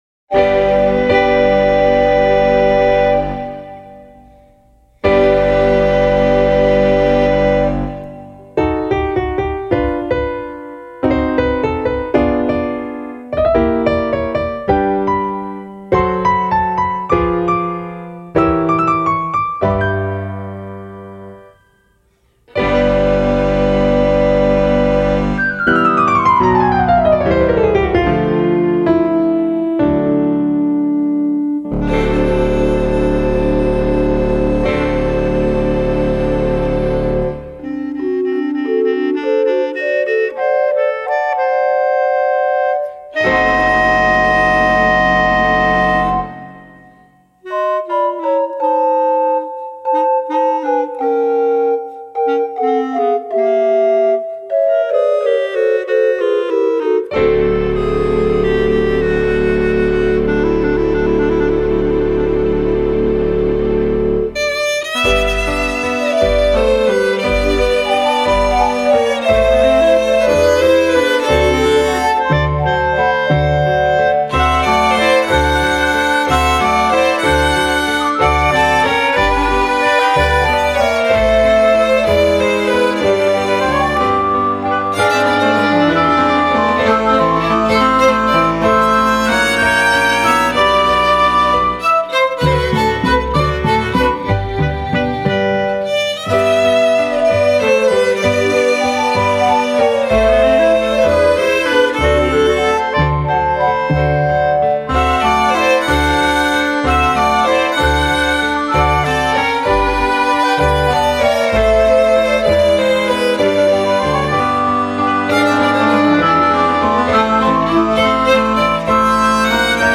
08:50:00   Valsa